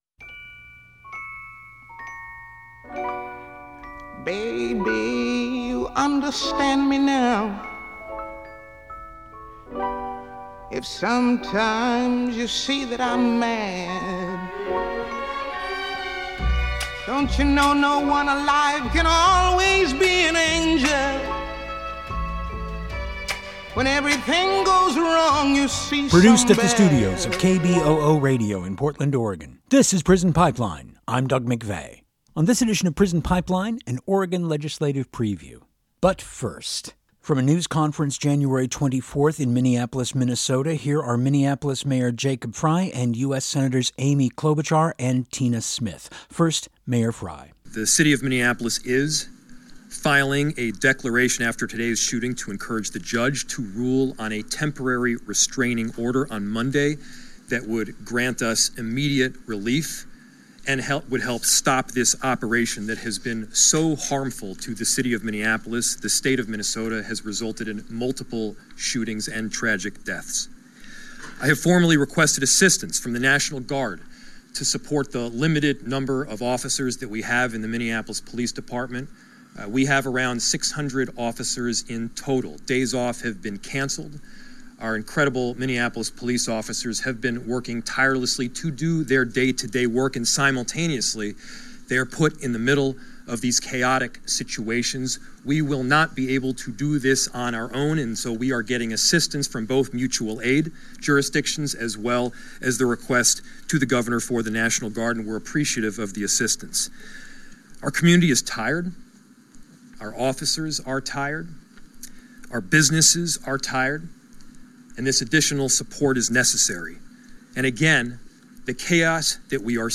This LC was discussed January 13 by the Oregon House Interim Committee on the Judiciary.